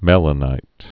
(mĕlə-nīt)